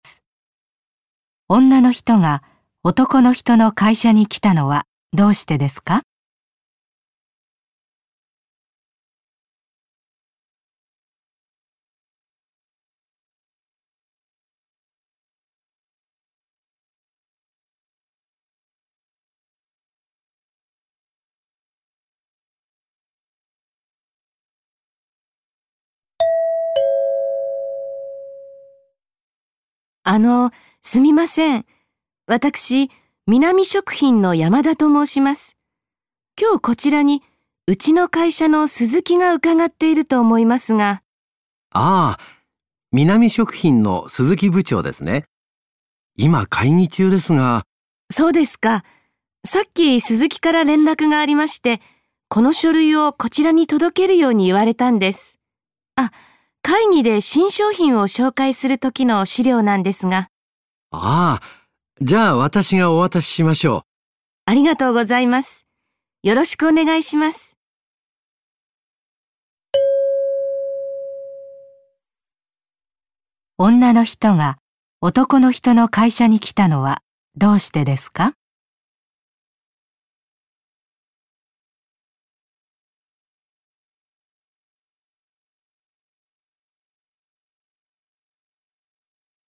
問題２：このもんだいでは、まず質問を聞いてください。そのあと、問題用紙を見てください。読む時間があります。それからはなしを聞いて、問題用紙の1から4の中から、正しい答えを一つえらんでください。